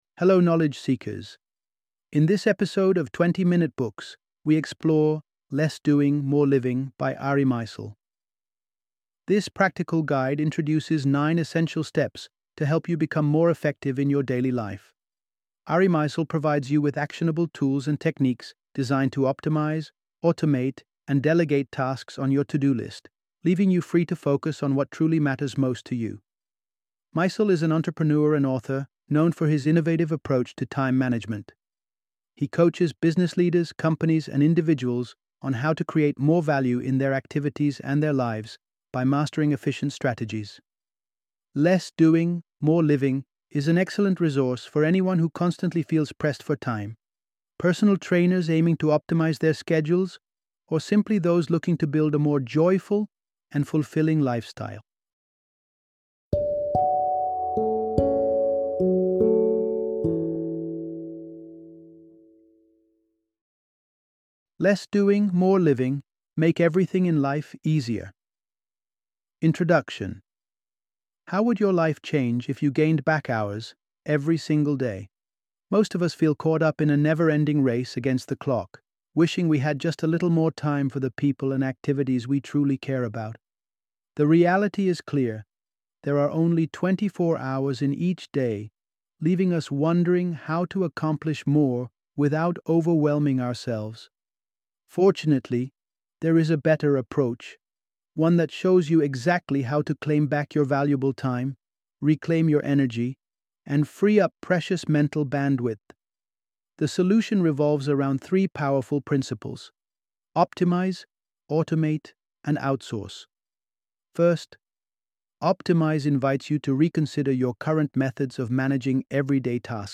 Less Doing, More Living - Audiobook Summary